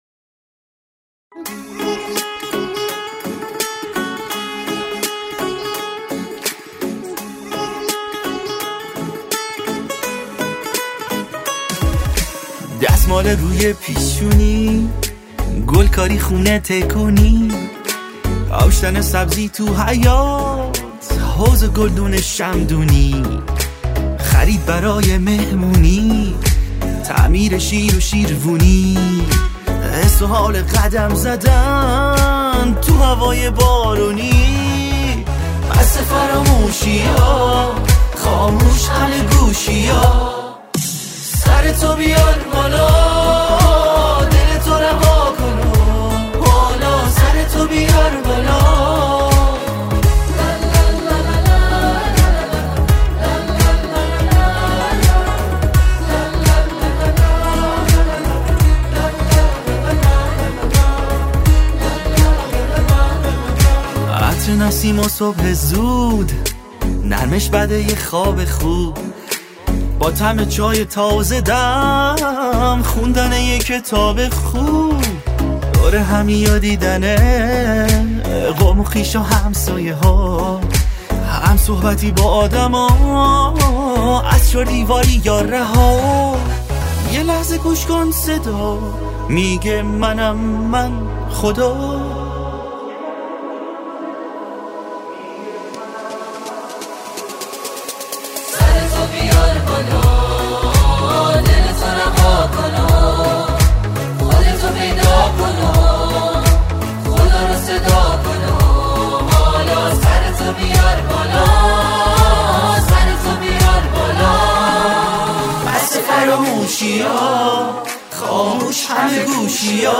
آهنگ های تیتراژ
ملودی زیبا و متن تأمل برانگیز، از ویژگی های این آهنگ است.